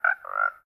sounds / mob / frog / idle4.ogg